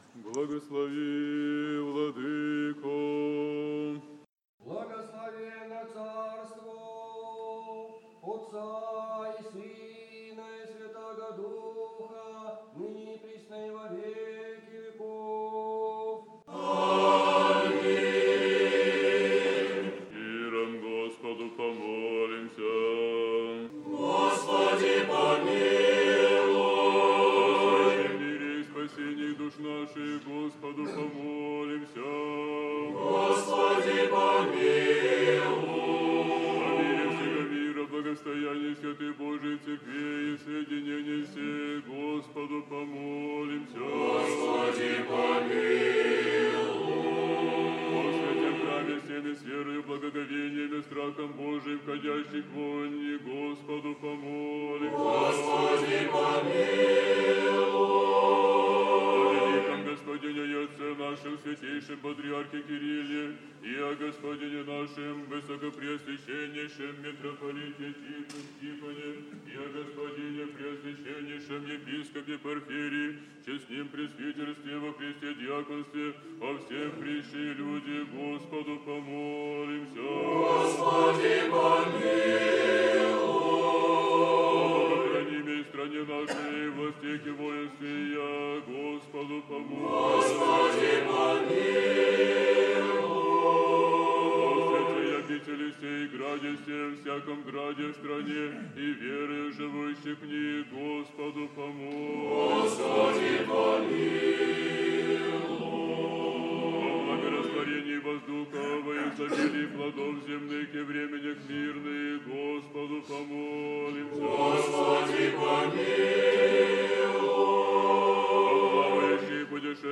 5 февраля 2020 года, в 14 годовщину отшествия ко Господу архимандрита Иоанна (Крестьянкина), митрополит Псковский и Порховский Тихон совершил Божественную Литургию в Михайловском соборе Свято-Успенского Псково-Печерского монастыря, сообщает пресс-служба Псковской епархии.
Песнопения Божественной Литургии исполнили: любительский хор обители
братский хор монастыря